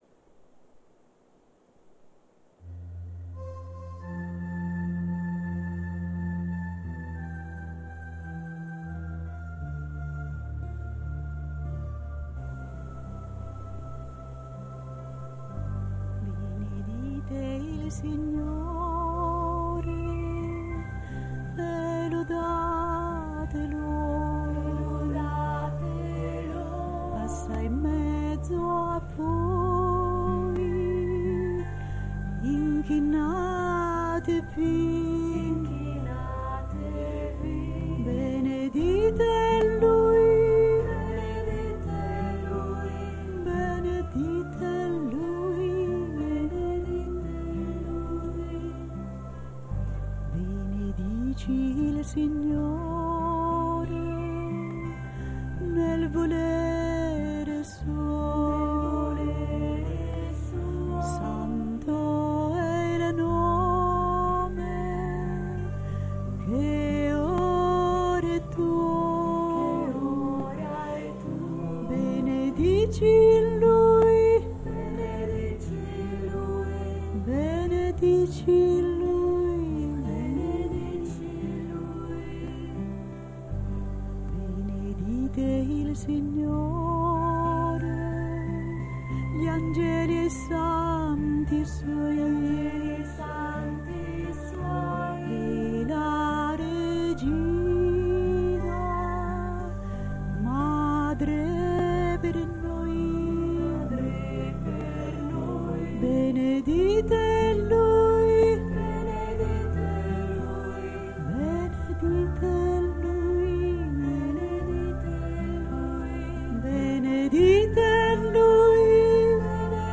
nr. 19 Preghiera mp3 Benedizione canto mp3